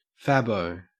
Ääntäminen
Ääntäminen AU Haettu sana löytyi näillä lähdekielillä: englanti Käännöksiä ei löytynyt valitulle kohdekielelle.